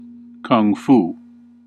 Ääntäminen
France: IPA: [kuŋ.fu]